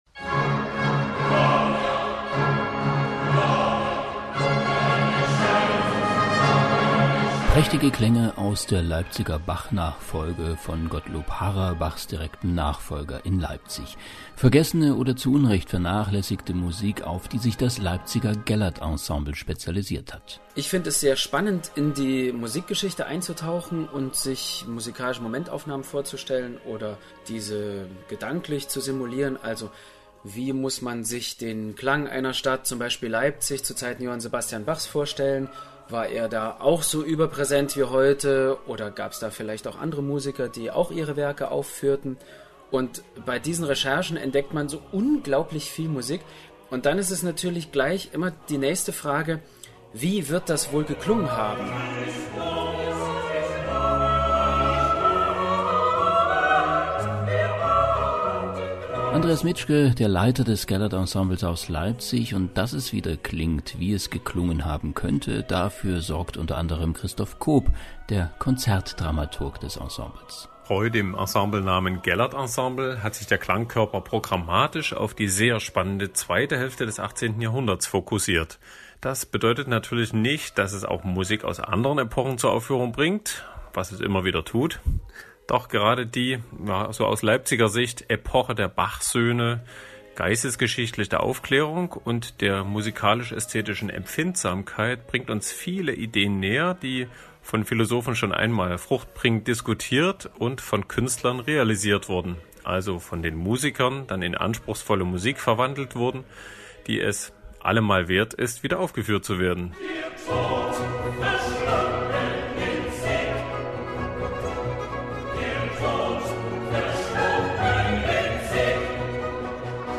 Vorbericht